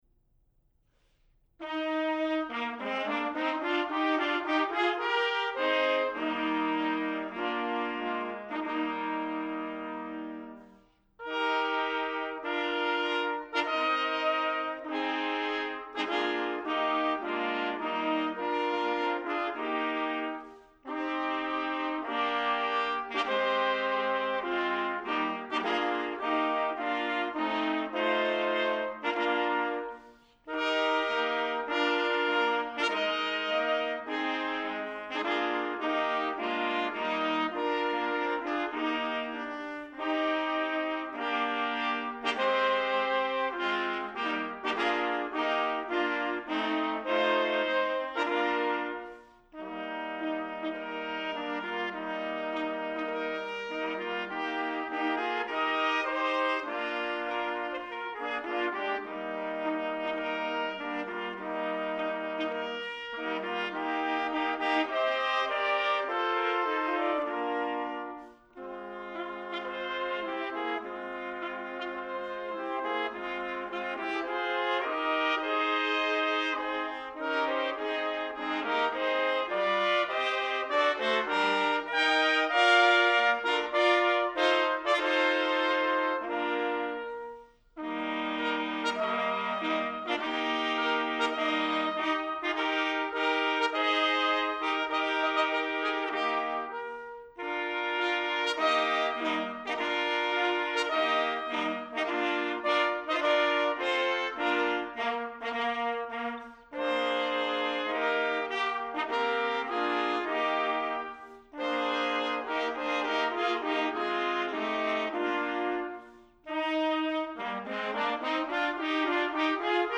Jazz Ensemble